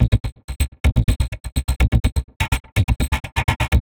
tx_perc_125_scrunchgated.wav